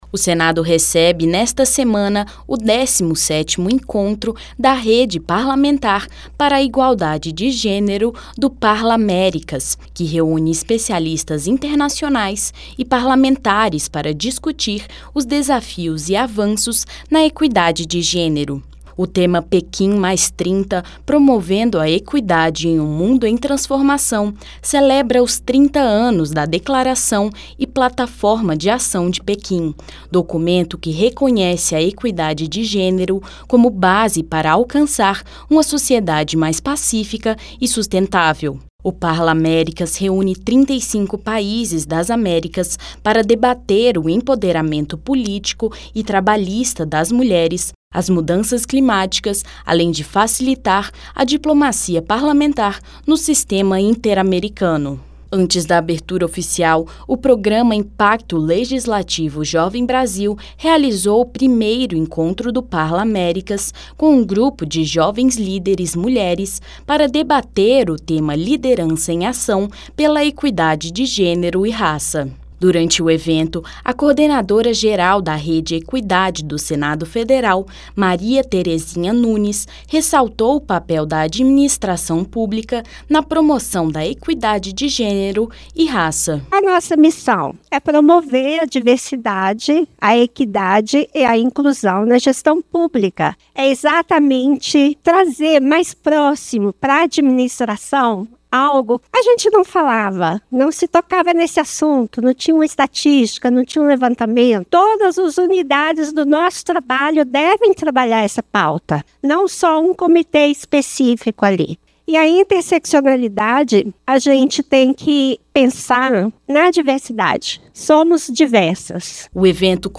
Rádio Senado : Notícias.